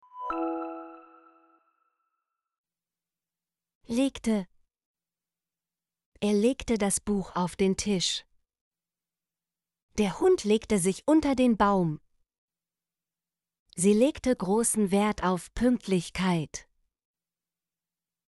legte - Example Sentences & Pronunciation, German Frequency List